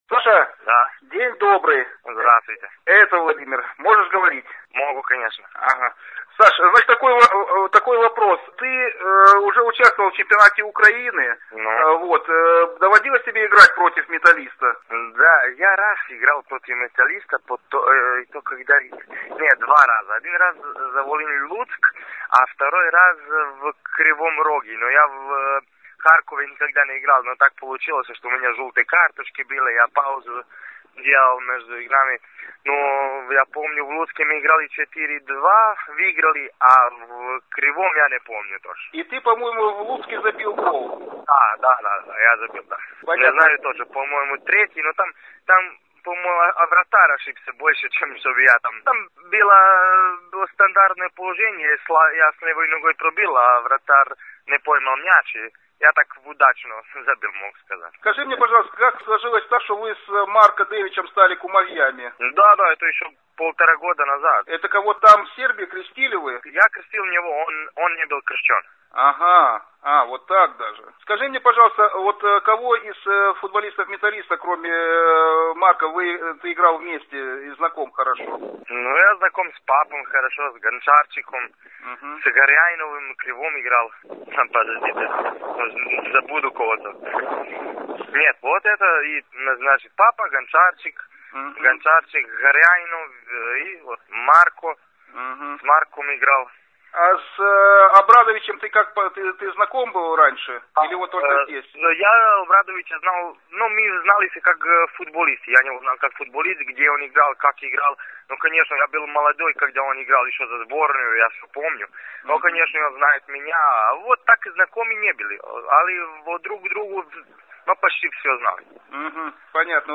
Интервью по телефону